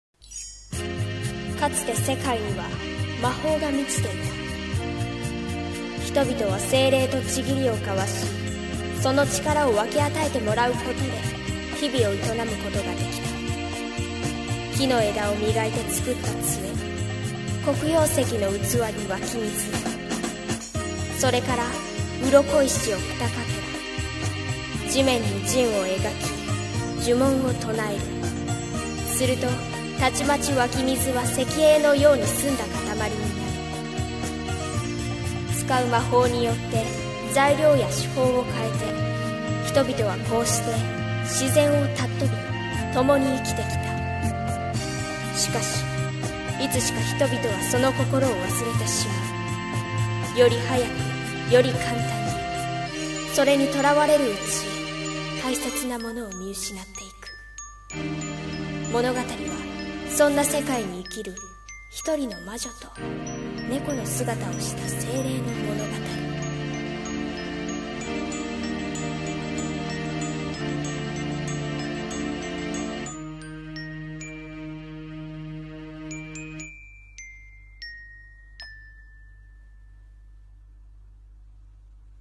【声劇】世界にひとつのまじないを